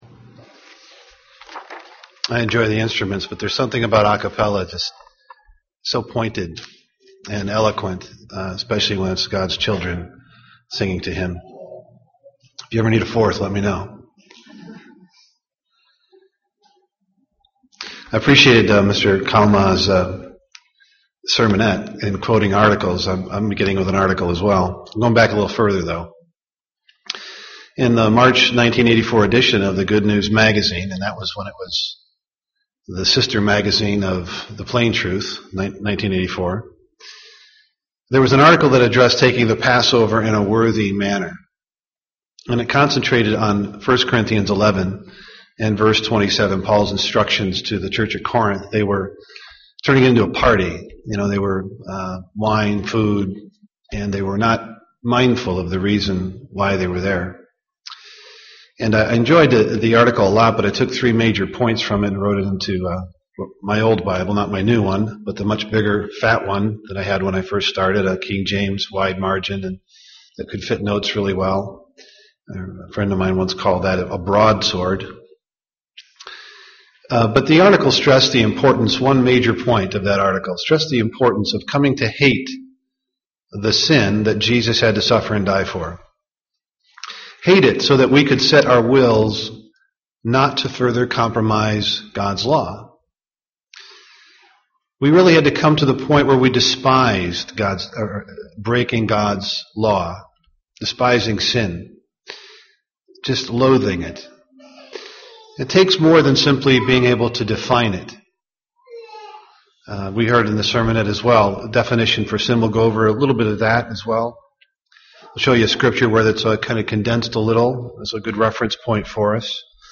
Print 5 Things That Sin Destroy 1) Innocence (Matthew 18:1-5) 2) Ideals (Matthew 19:16-22) 3) Will (Hebrews 3:7-9) 4) Freedom (Psalms 119:41-45) 5) Life (Romans 6:20-23) UCG Sermon Studying the bible?